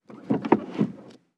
Palanca de cambios de un coche
palanca de cambios
Sonidos: Transportes